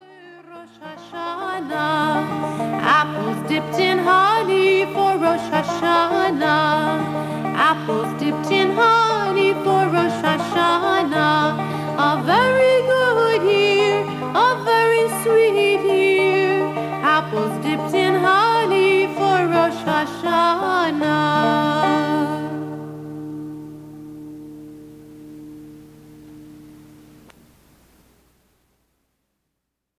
A Jewish sing-a-long for families!